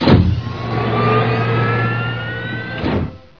doormove7.wav